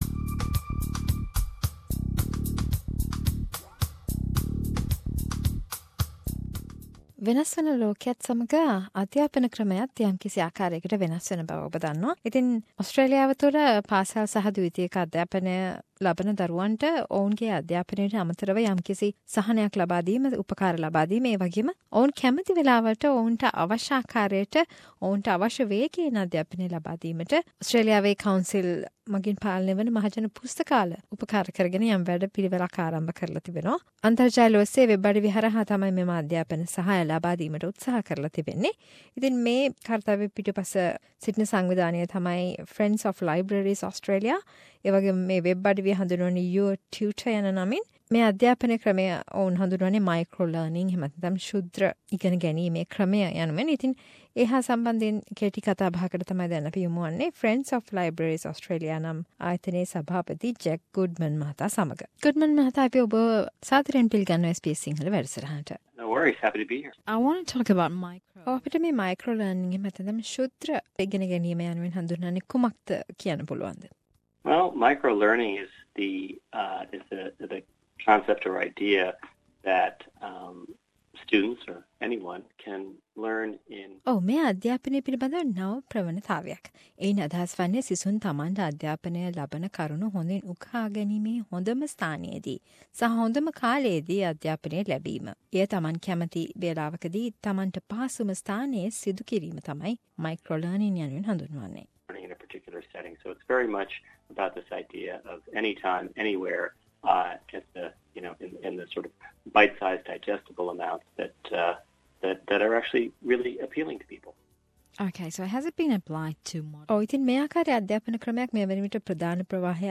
A discussion with Friends of Libraries about new pathways to education when and wherever students need.